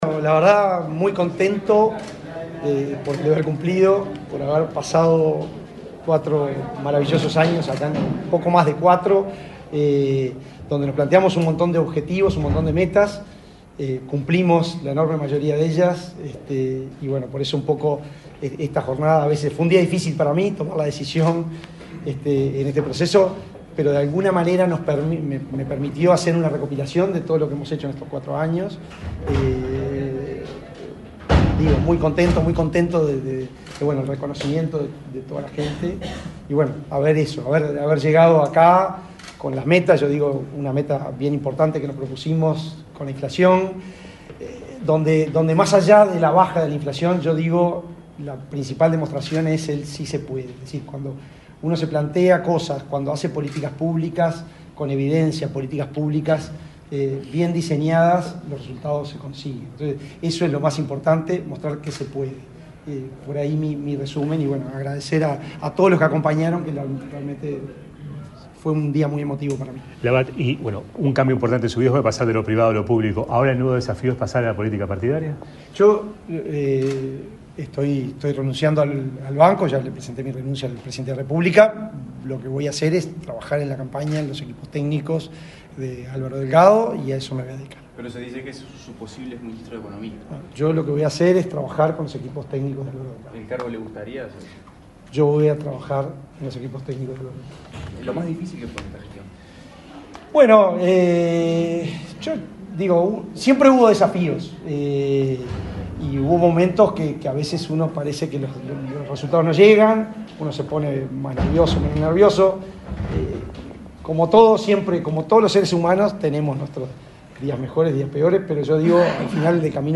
El presidente saliente del BCU, Diego Labat, dialogó con la prensa, luego de participar de la ceremonia de asunción de su sucesor al frente del